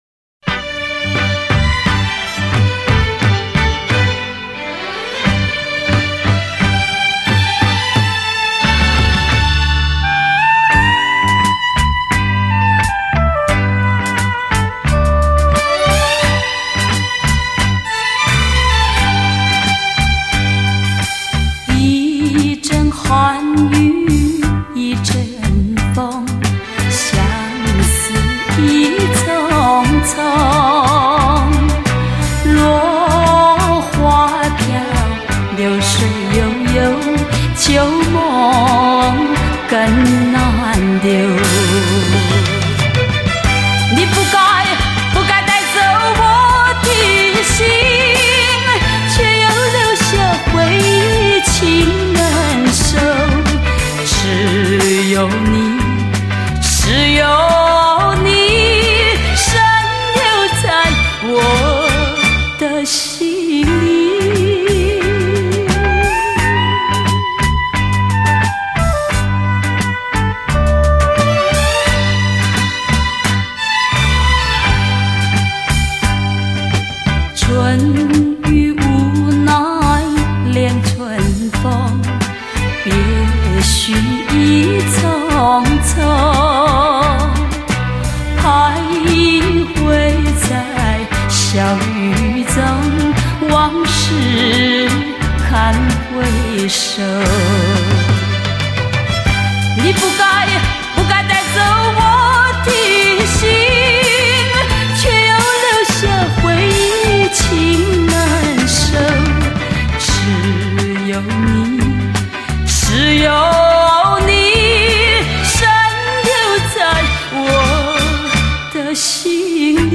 本套全部歌曲皆数码系统重新编制
音场透明度及层次感大为增加 并使杂讯降为最低 在任何音响组合中均可发挥最完美音色